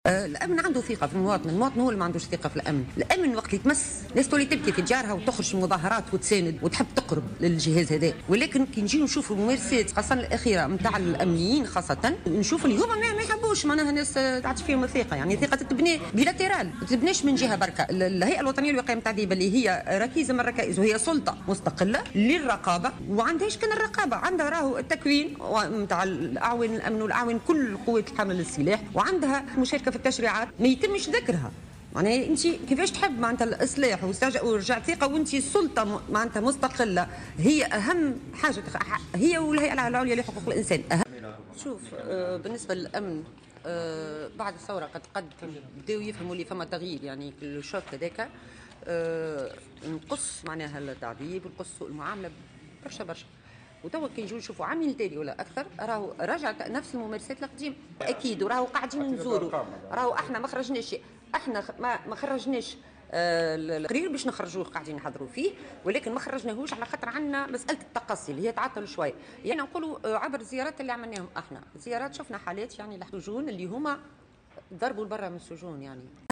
وأضافت في تصريح لمراسلة "الجوهرة أف أم" على هامش إستشارة وطنية حول مشروع خطة العمل المشتركة لتعزيز الثقة بين المواطن وقطاع الأمن، أن الهيئة رصدت جملة من التجاوزات أثناء الزيارات الميدانية التي قامت بها.